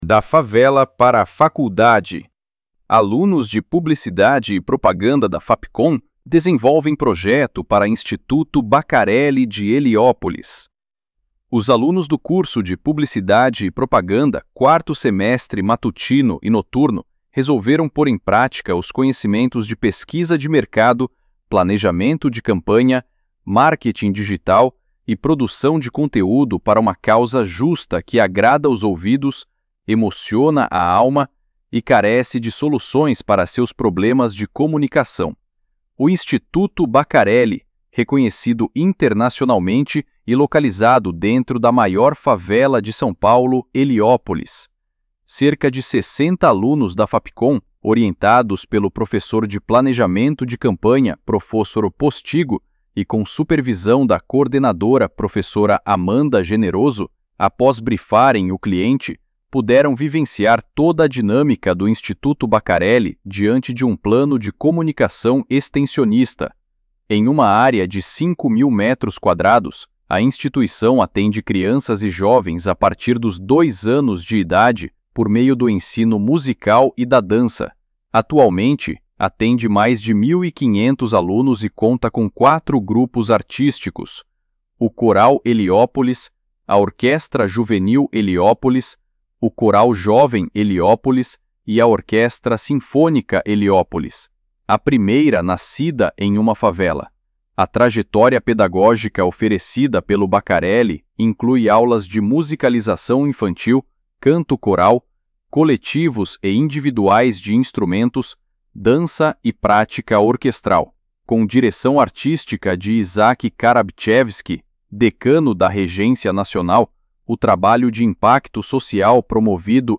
freepik__voiceover-generator__25081.mp3